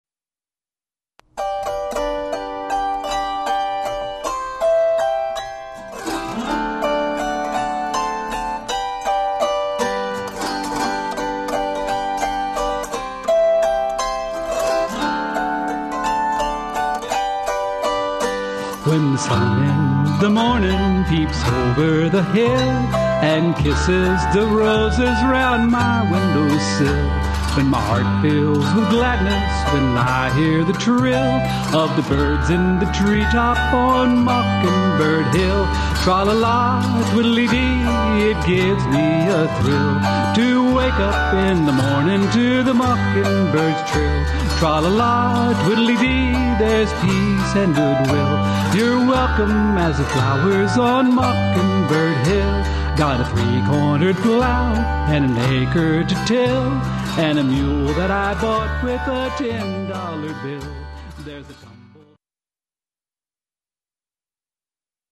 guitar and 5-string banjo but can hack at